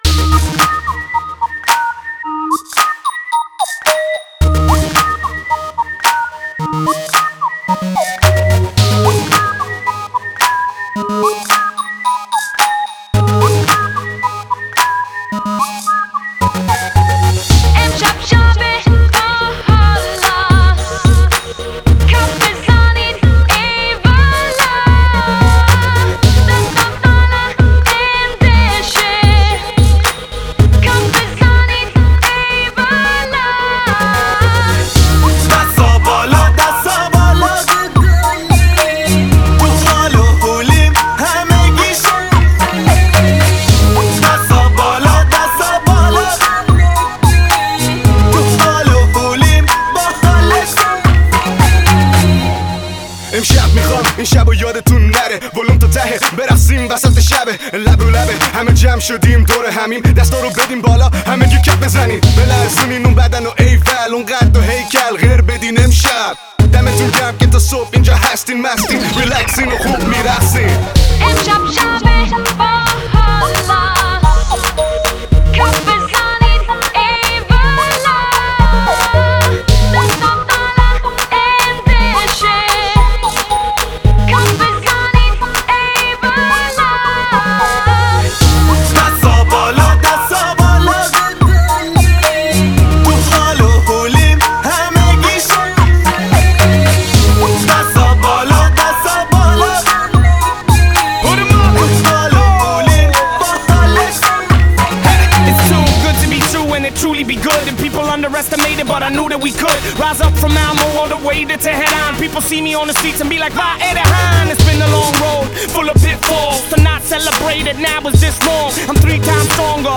Жанр: Pop, Dance; Битрэйт